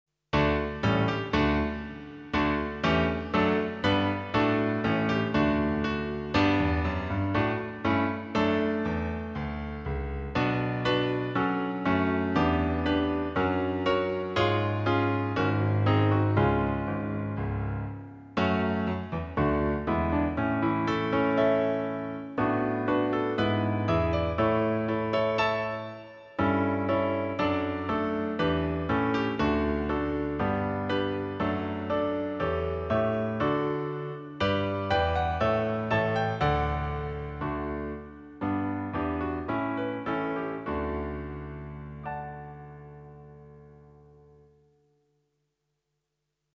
begeleiding